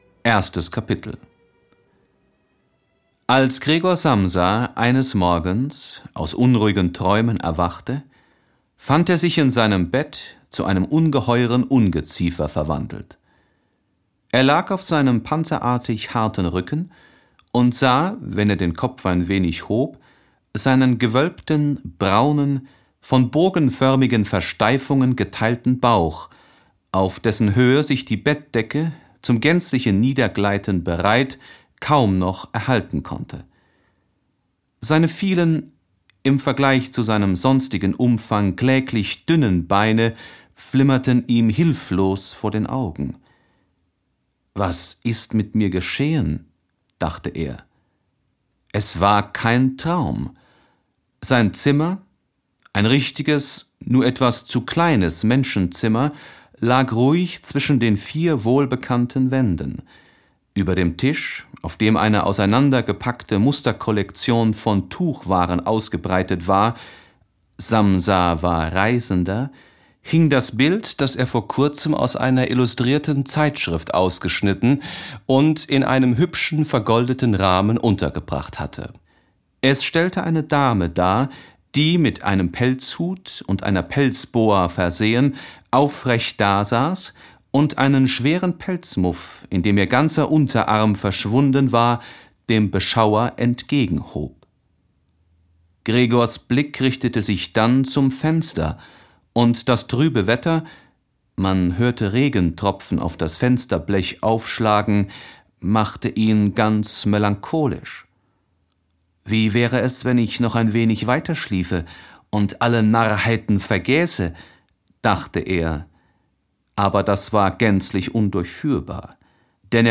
Die Verwandlung - Franz kafka - Hörbuch